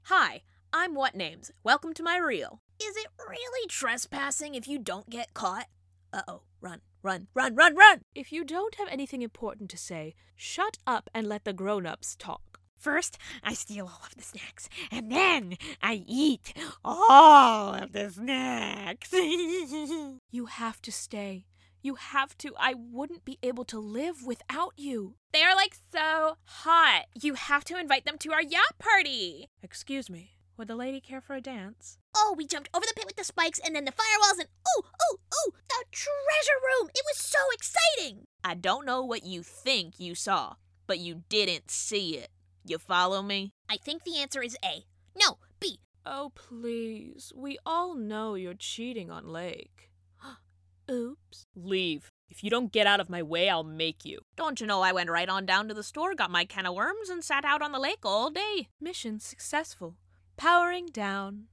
Voice Acting